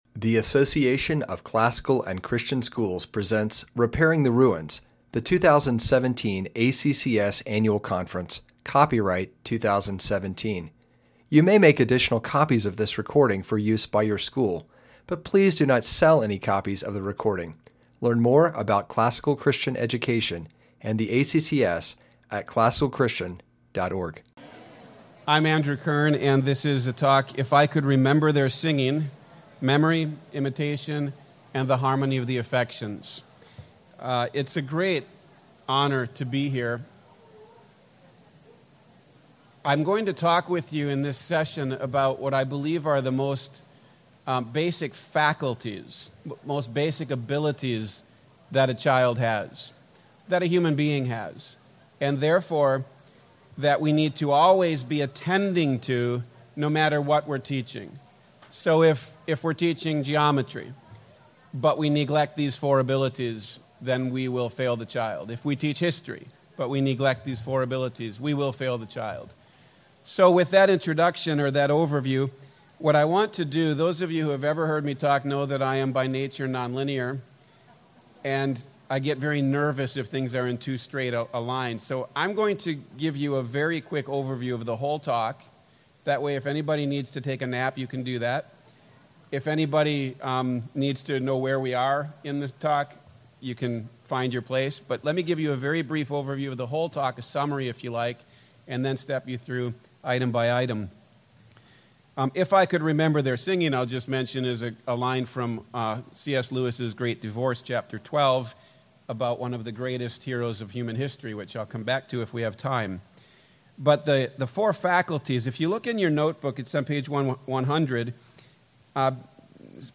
2017 Workshop Talk | 0:53:48 | All Grade Levels, General Classroom
This workshop explores ways we can transform these abilities into virtues, thus harmonizing the affections and ordering souls. Speaker Additional Materials The Association of Classical & Christian Schools presents Repairing the Ruins, the ACCS annual conference, copyright ACCS.